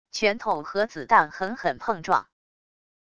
拳头和子弹狠狠碰撞wav音频